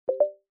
ding.mp3